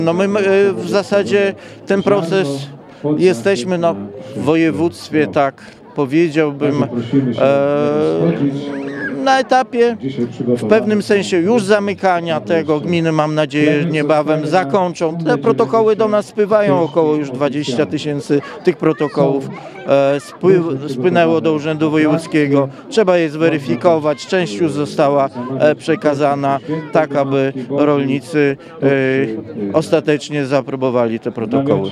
Wojewódzkie dożynki odbyły się dziś w pokamedulskim klasztorze nad Wigrami.
Bohdan Paszkowski, wojewoda podlaski zapewnia, że pieniądze zostaną wypłacone najszybciej, jak to możliwe.